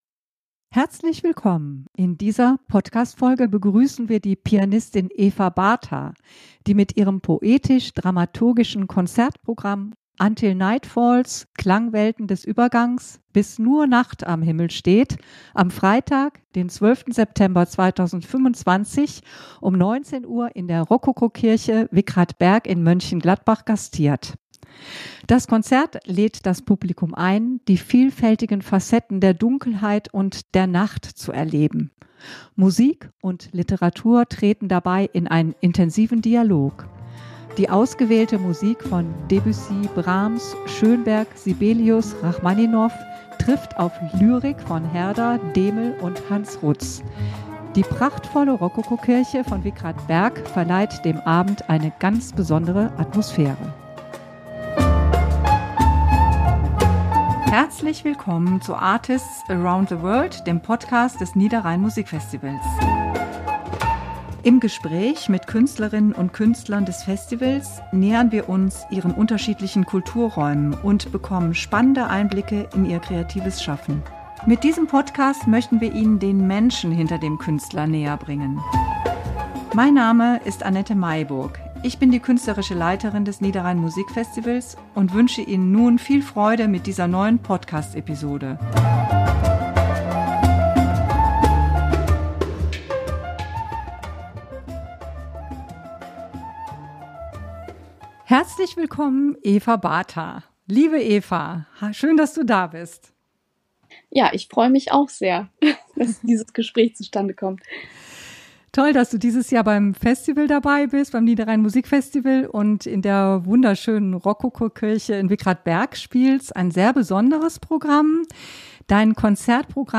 Interview ~ Artists around the world - Der Podcast zum Niederrhein Musikfestival Podcast